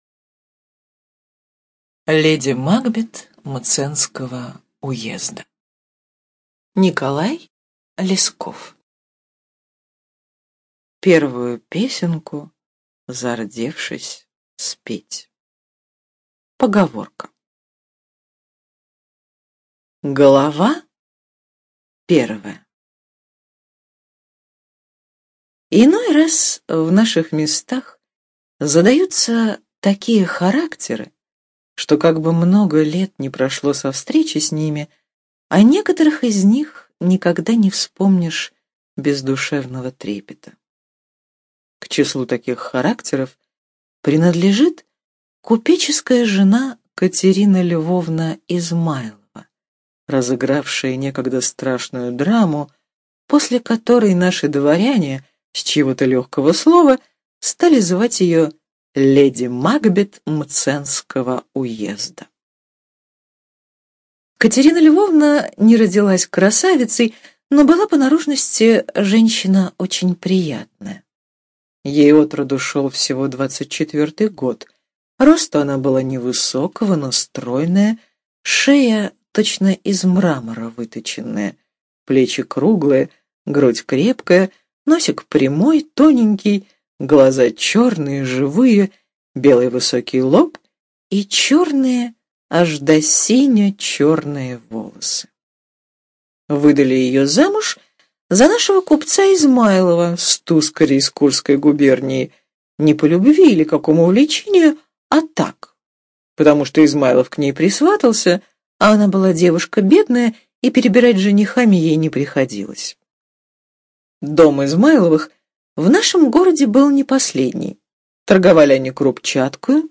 Леди Макбет Мценского уезда — слушать аудиосказку Николай Лесков бесплатно онлайн